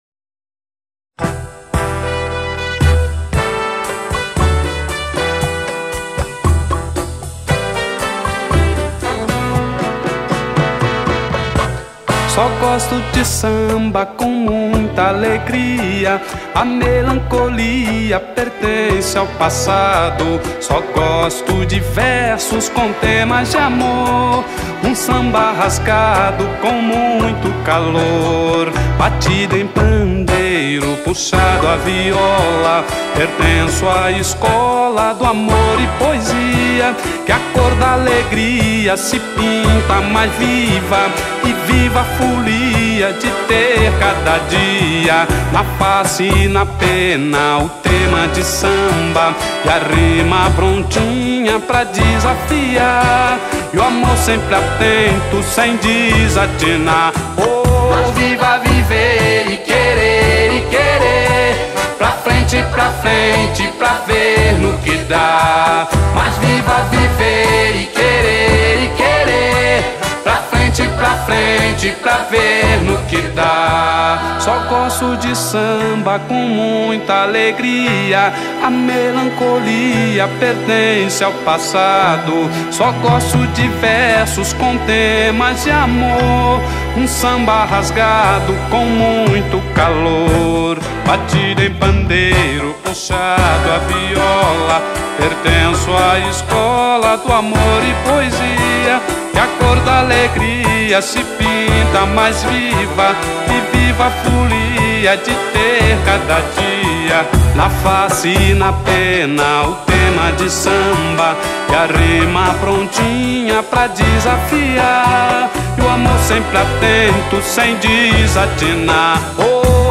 A Essência do Samba-Rock